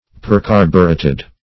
percarbureted - definition of percarbureted - synonyms, pronunciation, spelling from Free Dictionary
Search Result for " percarbureted" : The Collaborative International Dictionary of English v.0.48: Percarbureted \Per*car"bu*ret`ed\, a. (Chem.) Combined with a relatively large amount of carbon.